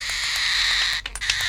bruit-etrange_NoTb8BcO.mp3